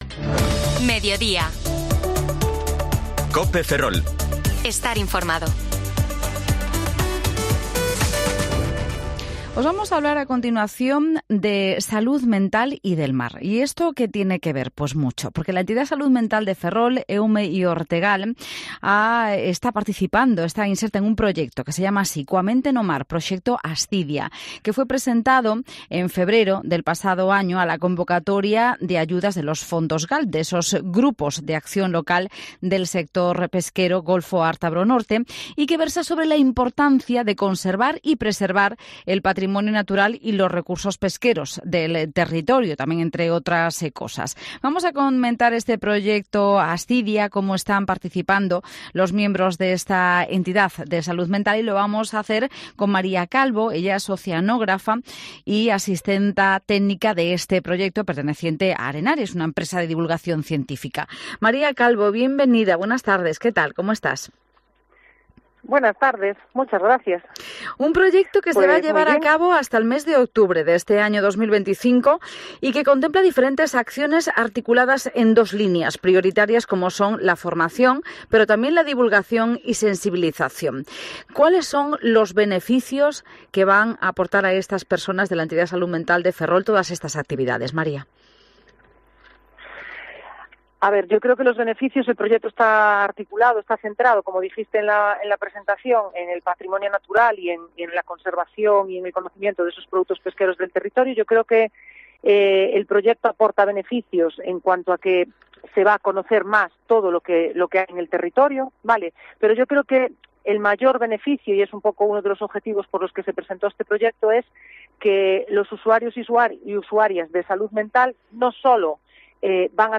No mes de febreiro participamos nunha entrevista na Radio COPE Ferrol, no programa MEDIODÍA.